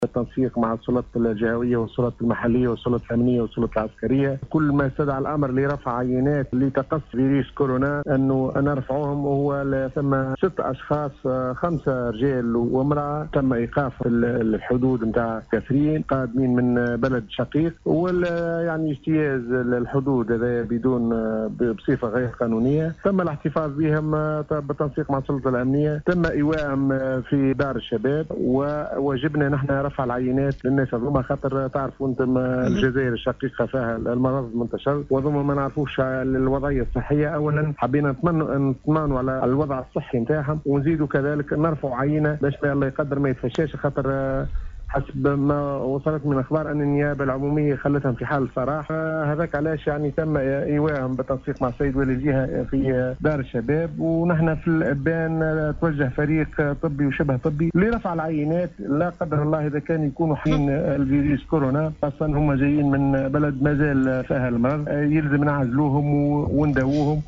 أكد المدير الجهوي للصّحة بالقصرين الدكتورعبد الغني الشّعباني في اتصال بإذاعة السيليوم أف أم اليوم الأحد  31 ماي 2020 ، أن الفريق الصحي قام أمس برفع عينات لـ 6 أفارقة 5 رجال و امرأة لتحليلها في المخبر المرجعي للتثبت إن كانوا مصابين بفيروس كورونا أم لا.
عبد-الغني-الشّعباني-.mp3